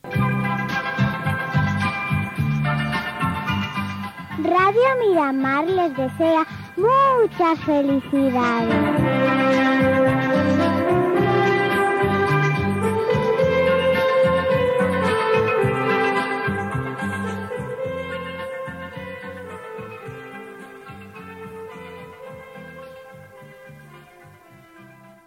Felicitació de Nadal amb la veu d'un nen